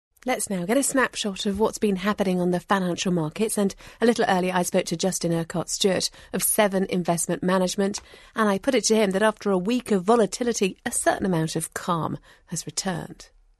【英音模仿秀】金融蹦极 听力文件下载—在线英语听力室